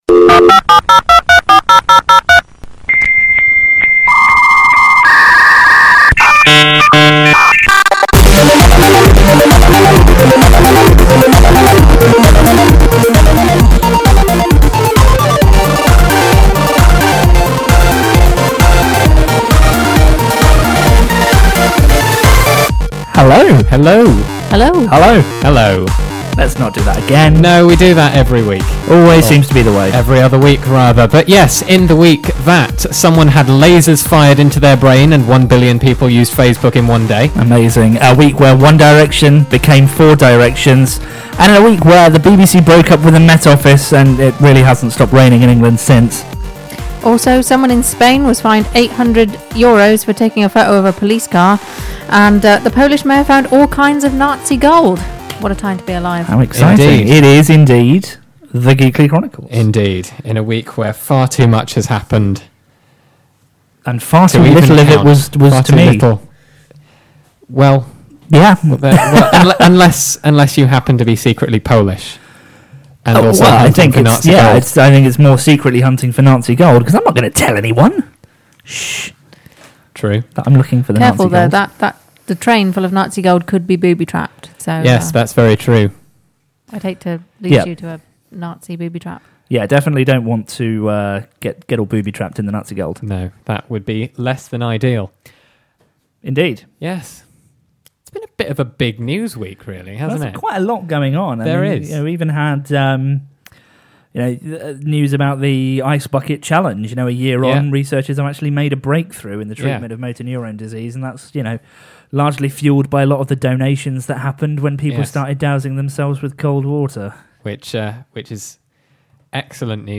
Our live listeners decided that Like A Princess was the worse song, with 60% of the vote.